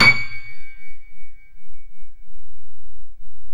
Index of /90_sSampleCDs/Roland L-CD701/KEY_Steinway ff/KEY_Steinway M